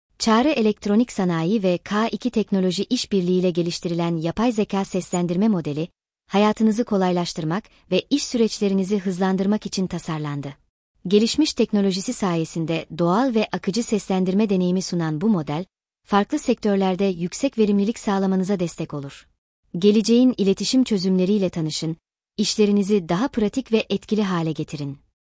Belediye Anonsları ve Ezan Sistemi
Yapay Zeka ile anons
Gelişmiş teknolojisi sayesinde doğal ve akıcı seslendirme deneyimi sunan bu model, farklı sektörlerde yüksek verimlilik sağlamanıza destek olur.